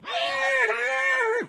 animalia_horse_death.ogg